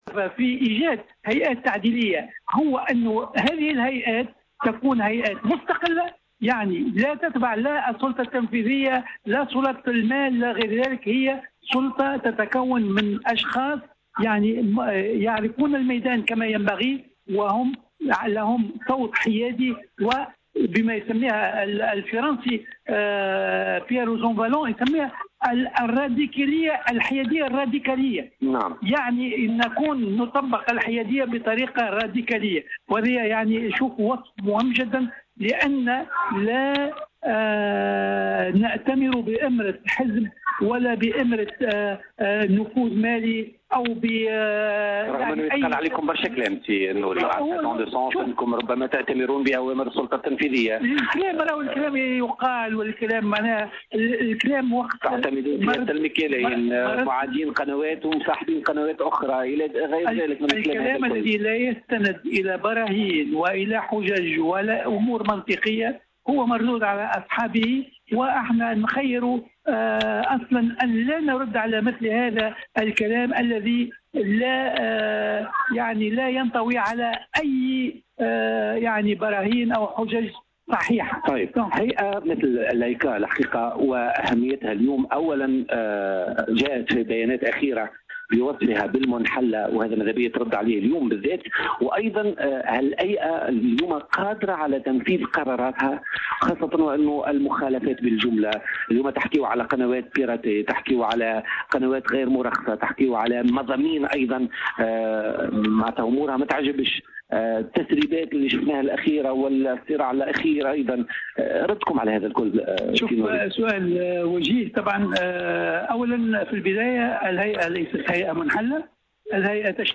وأضاف في مداخلة له في برنامج "بوليتيكا" على هامش الدورة 18 للمهرجان العربي للإذاعة والتلفزيون بالحمامات أن الهيئة محايدة ومستقلة ولا تعمل تحت إمرة أي جهة، مؤكدا أن الهيئة قانونية وليست "منحلّة" خلافا لكل ما قيل و أنها ستواصل عملها إلى حين انتصاب المجلس المقبل للهيئة المقبلة، وفق تعبيره.